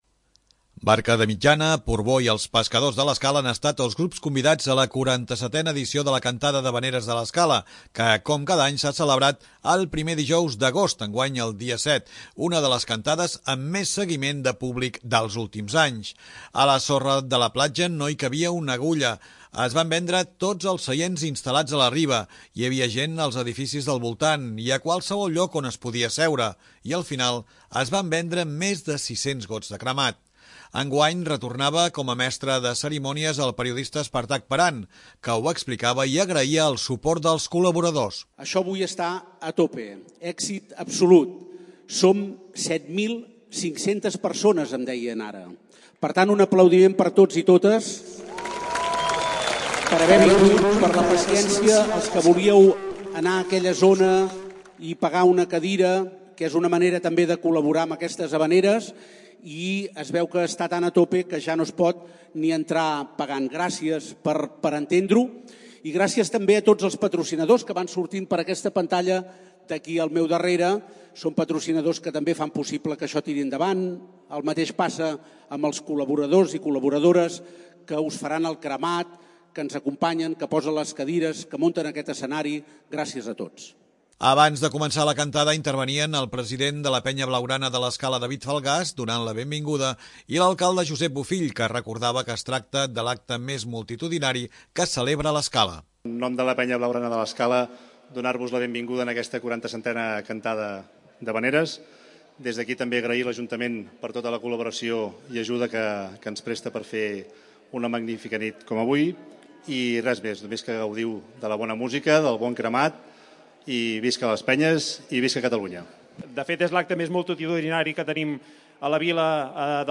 Barca de Mitjana, Port Bo i els Pescadors de l'Escala han estat els grups convidats a la 47ena edició de la Cantada d'havaneres de l'Escala, que com cada any s'ha celebrat el primer dijous d'agost, enguany el dia 7, una de les cantades amb més seguiment de públic dels últims anys.
Una de les sorpreses de la nit va ser que pujava a l'escenari el conegut presentador de Televisió Espanyola, amb llarga trajectòria al programa Saber i Ganar, Jordi Hurtado.